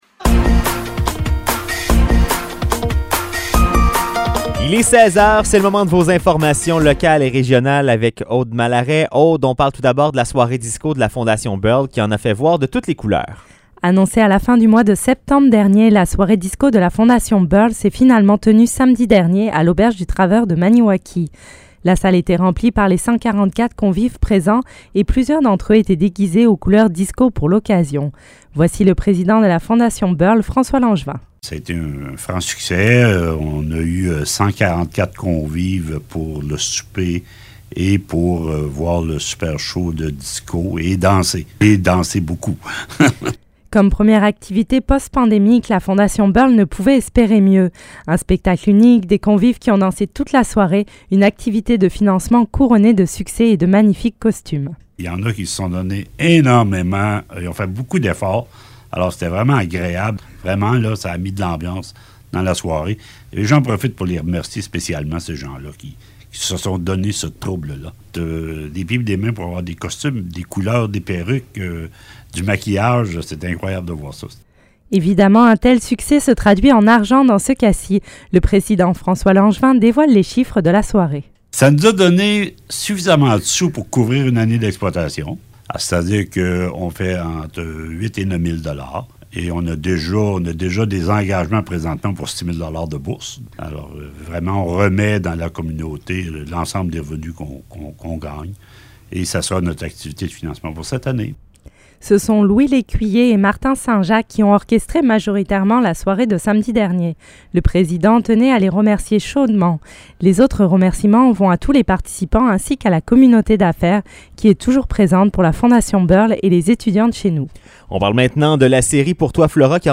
Nouvelles locales - 1er novembre 2022 - 16 h